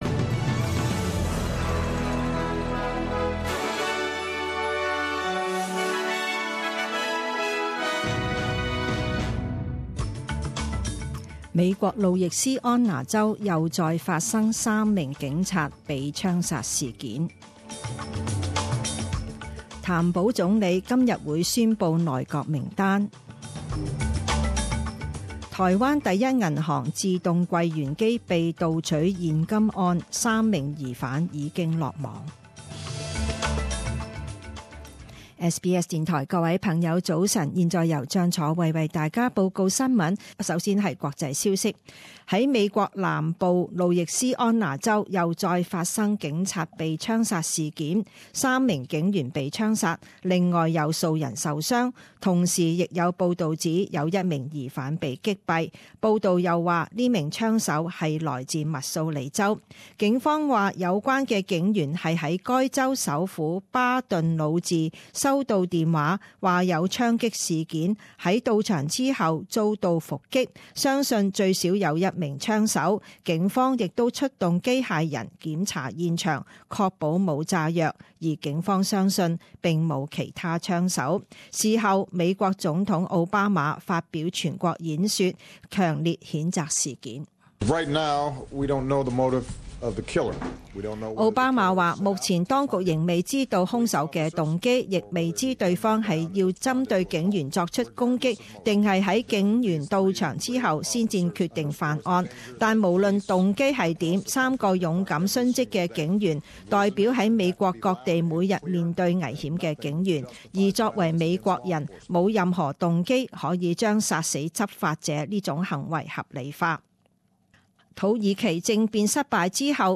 七月十八日十點鐘新聞報導